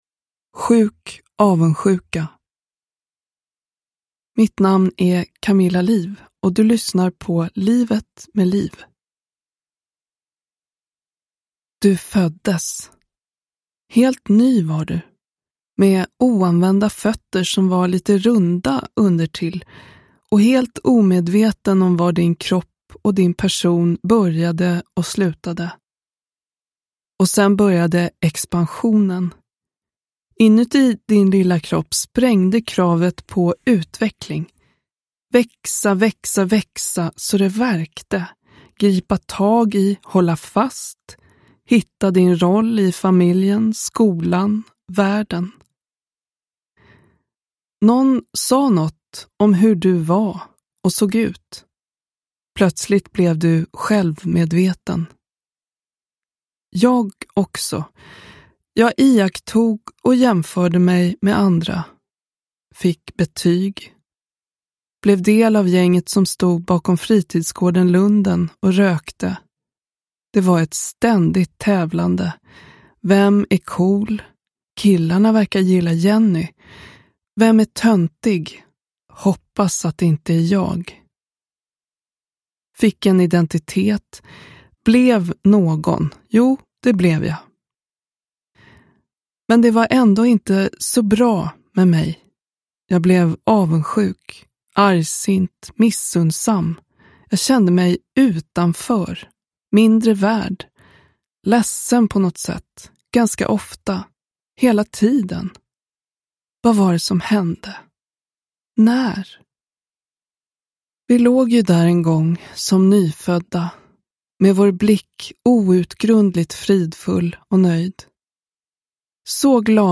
Livet med Lif - 4 - Sjuk avundsjuka – Ljudbok – Laddas ner